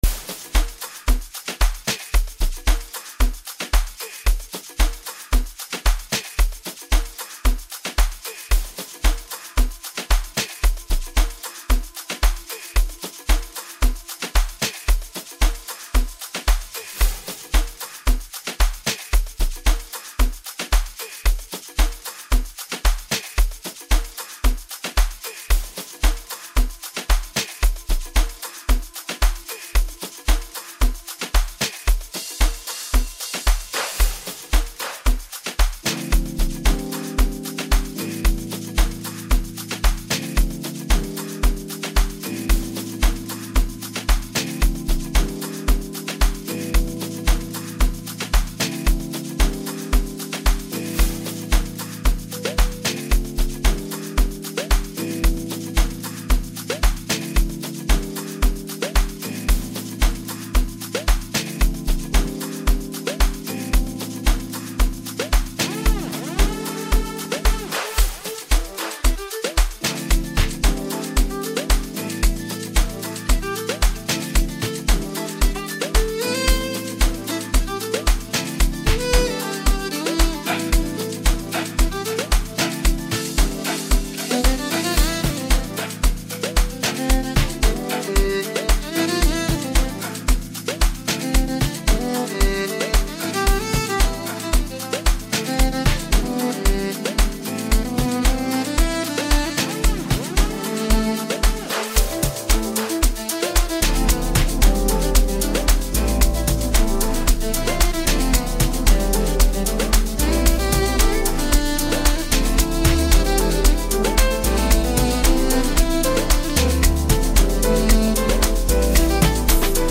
South African singer-songsmith